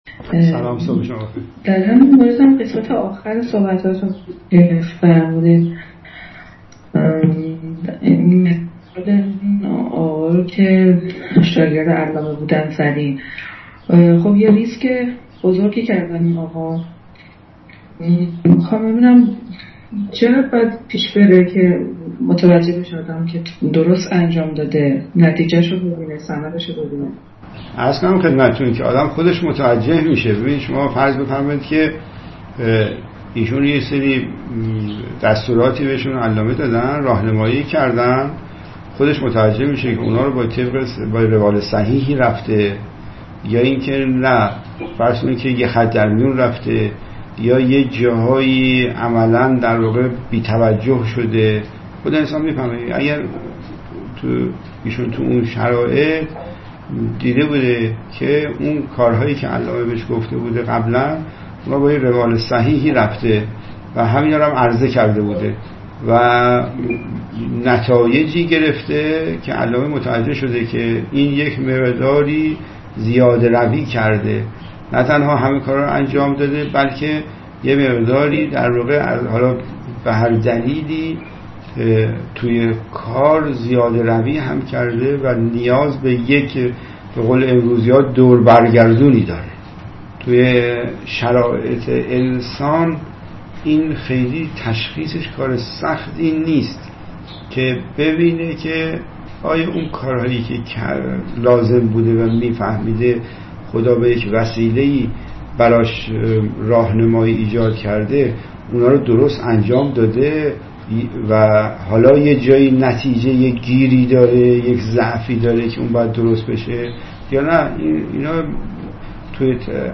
متن : مسئولیّت تصمیم‌گیری و گرفتاری‌های آن (پرسش‌وپاسخ)